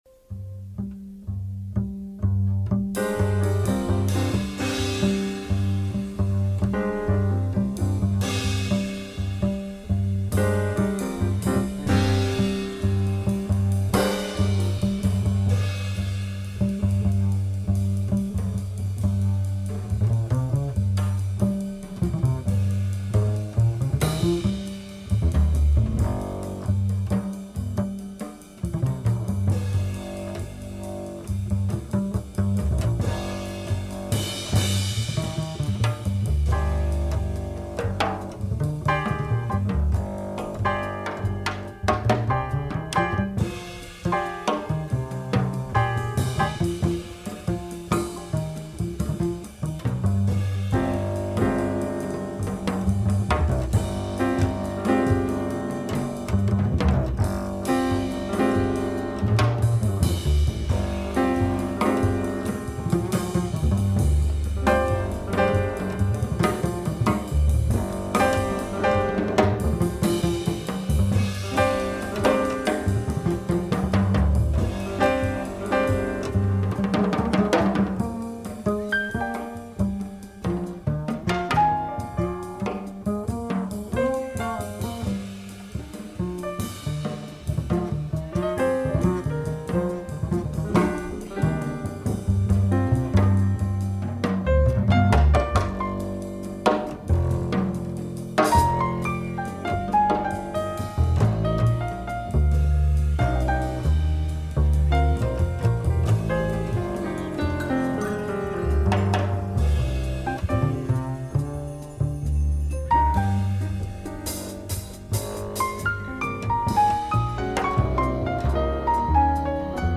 Deep Japanese jazz with superb bamboo flute
keys
drums
bass